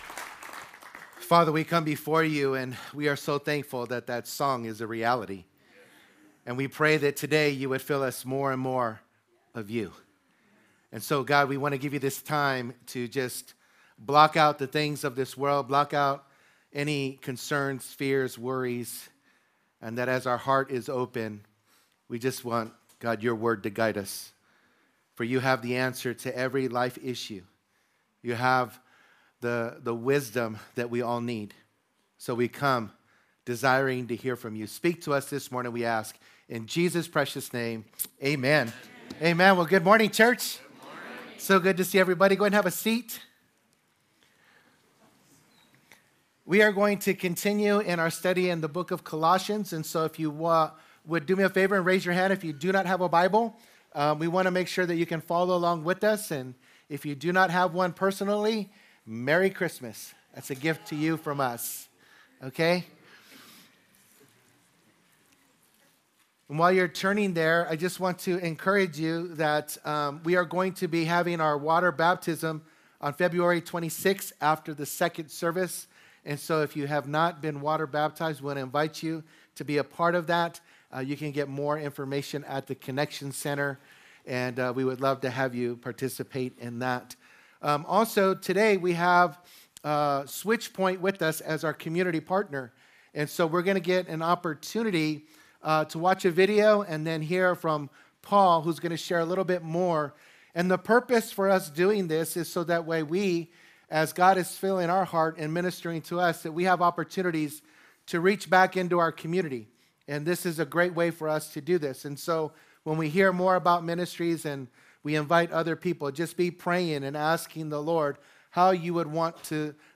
Calvary Chapel Saint George - Sermon Archive
Related Services: Sunday Mornings